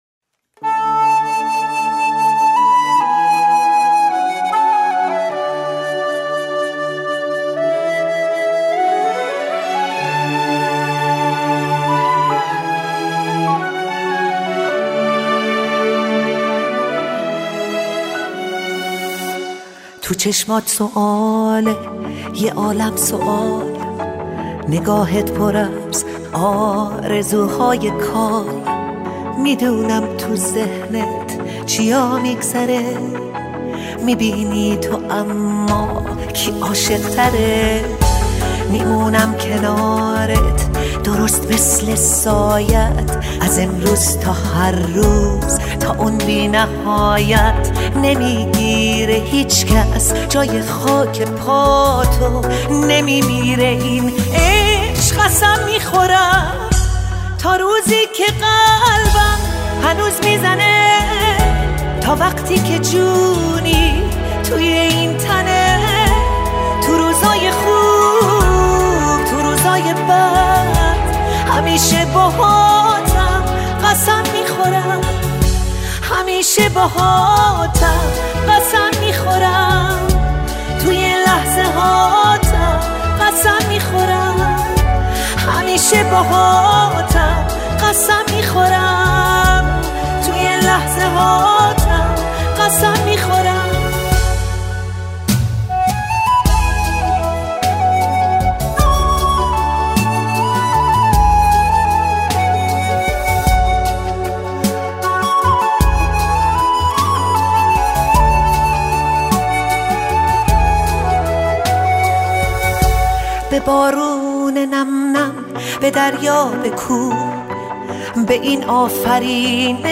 اهنگ تانگو فارسی